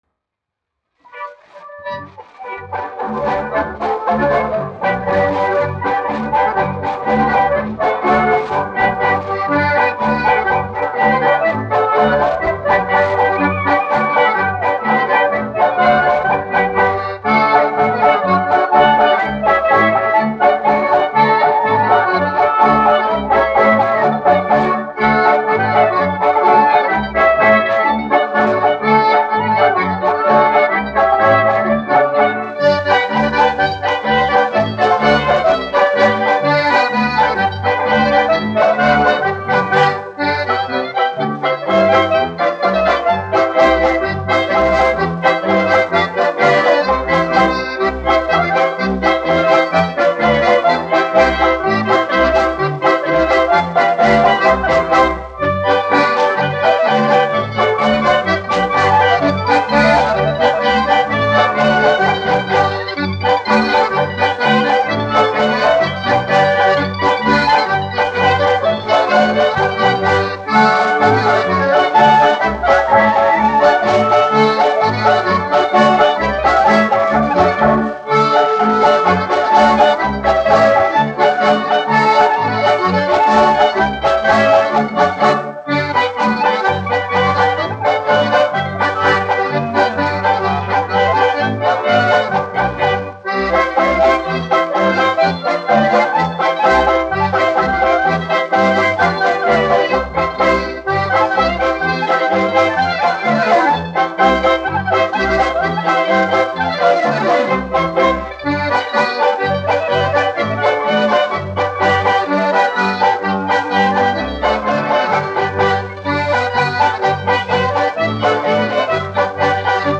1 skpl. : analogs, 78 apgr/min, mono ; 25 cm
Polkas
Populārā instrumentālā mūzika